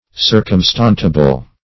Search Result for " circumstantiable" : The Collaborative International Dictionary of English v.0.48: circumstantiable \cir`cum*stan"tia*ble\ (s[~e]r`k[u^]m*st[a^]n"sh[.a]*b'l) a. Capable of being circumstantiated.
circumstantiable.mp3